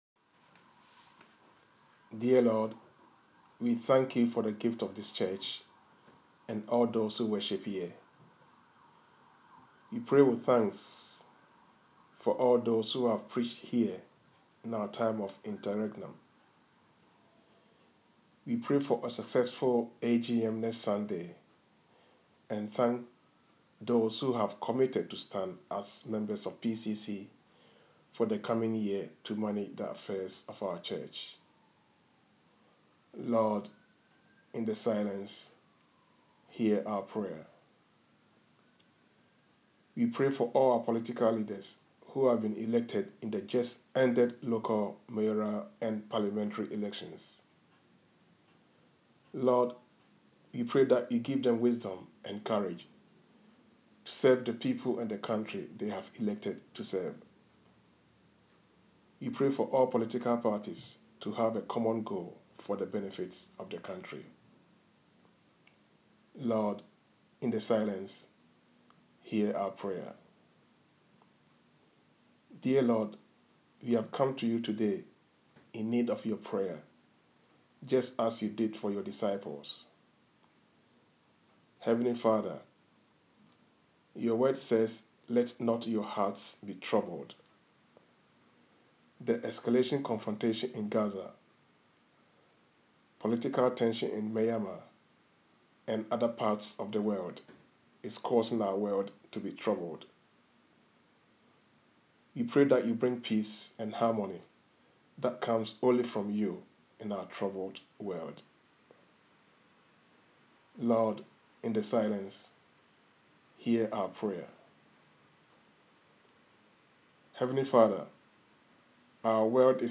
Intercessions